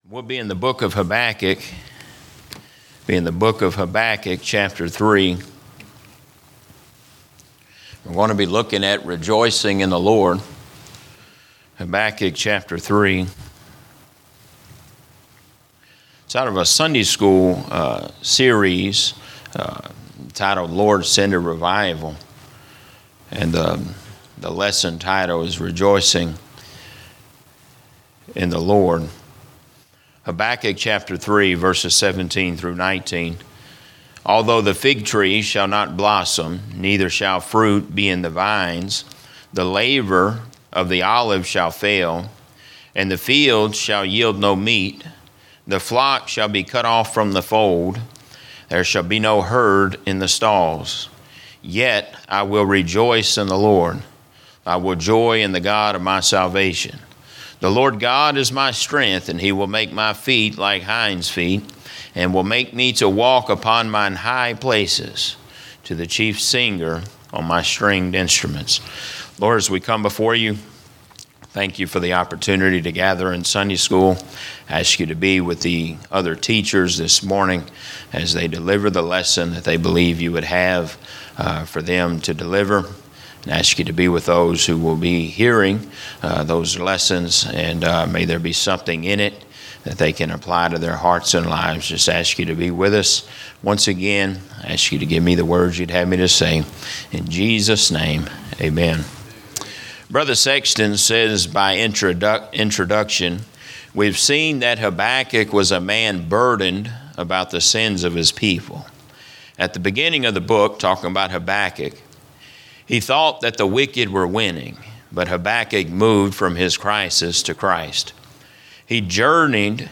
Teaching - Emmanuel Baptist Church